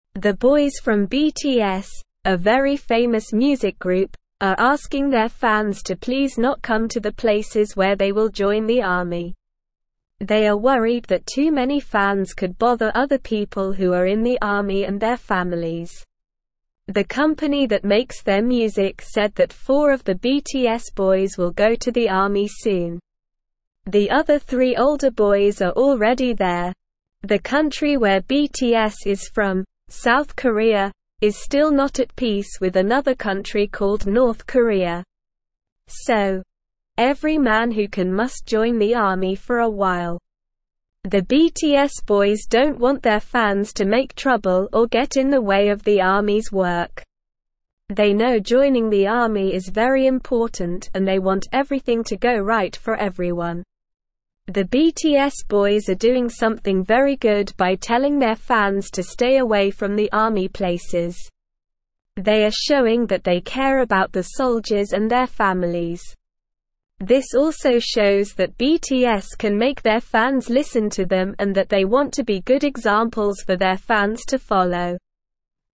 Slow
English-Newsroom-Lower-Intermediate-SLOW-Reading-BTS-Boys-Ask-Fans-to-Stay-Away-from-Army.mp3